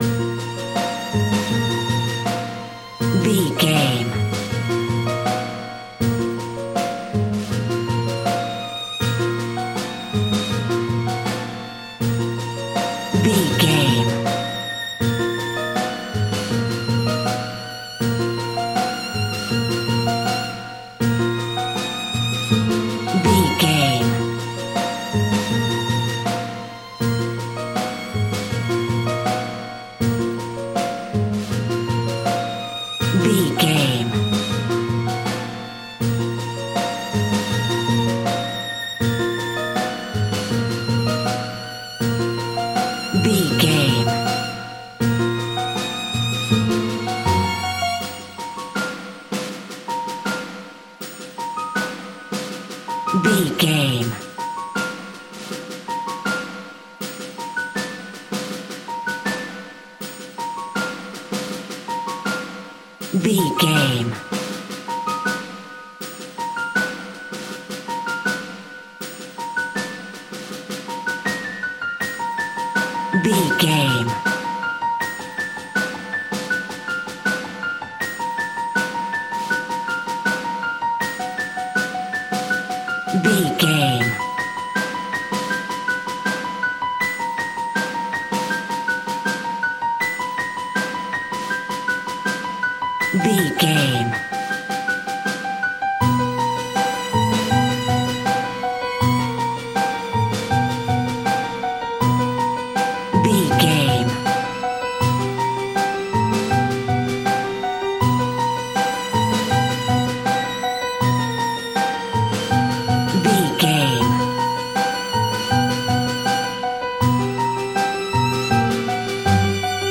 In-crescendo
Thriller
Aeolian/Minor
tension
ominous
suspense
haunting
eerie
synthesizers
Synth Pads
atmospheres